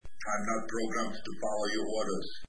Here are some sounds I grabbed from Terminator 3 during play.